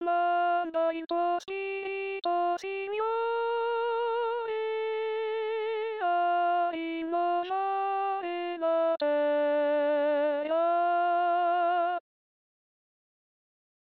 Pentecoste